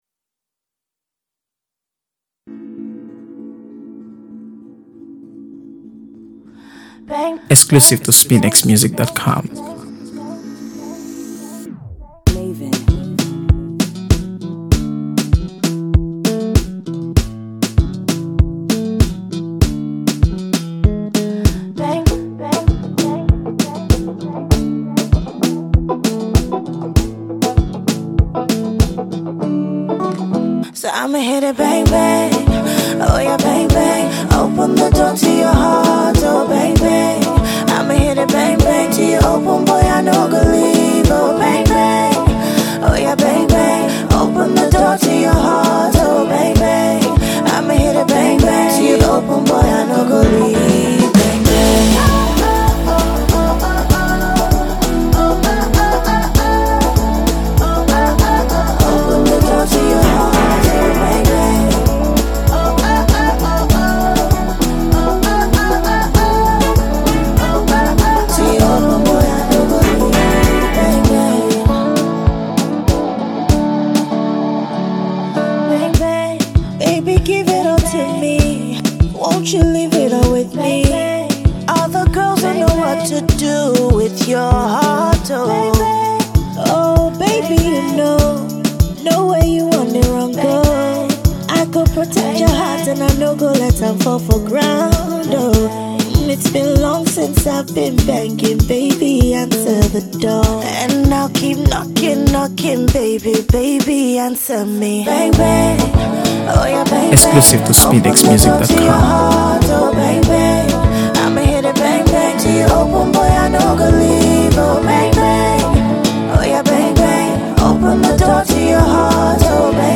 AfroBeats | AfroBeats songs
Nigerian singer-songwriter and performer